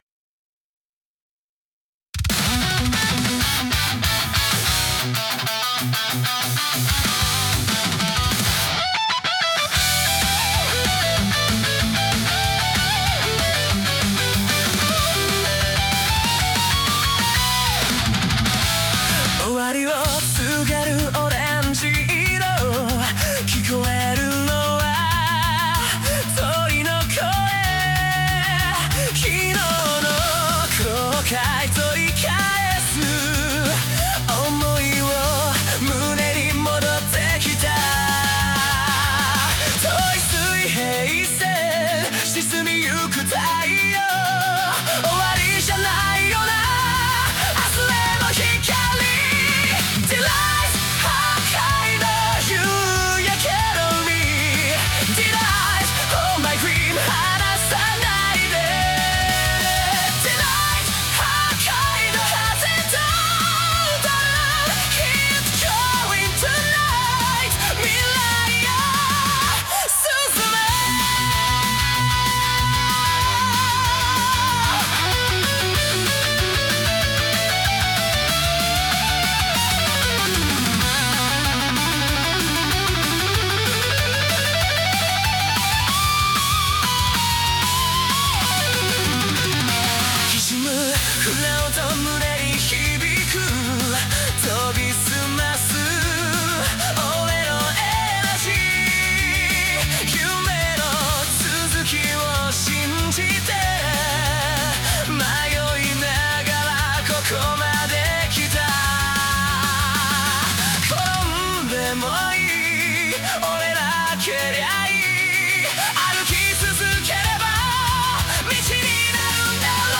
男性ボーカル
Hi-NRG ver.